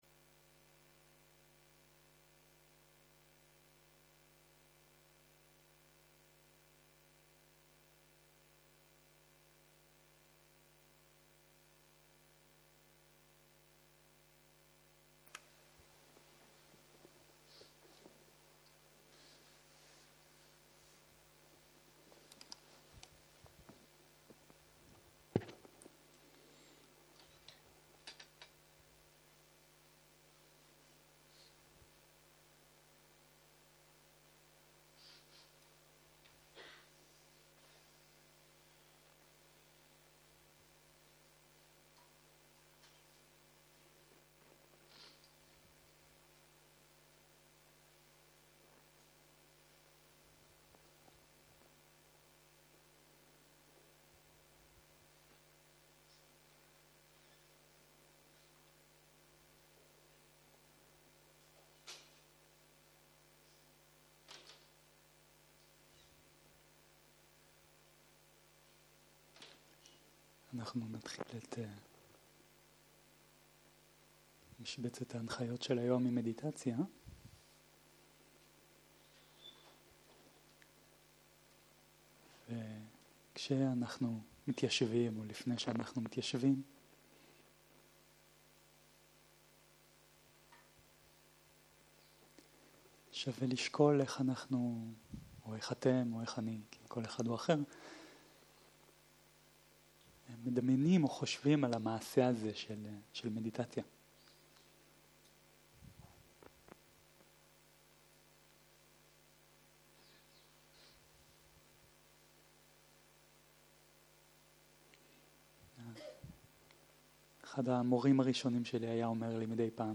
שיחת הנחיות למדיטציה
ריטריט חנוכה